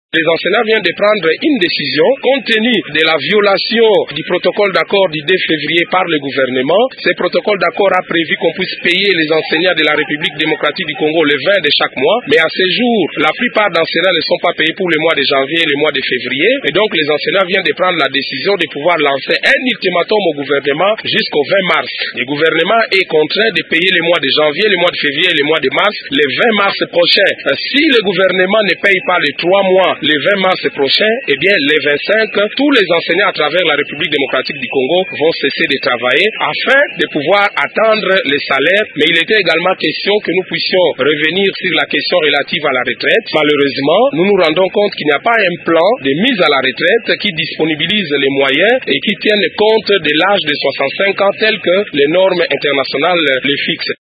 Le syndicaliste explique: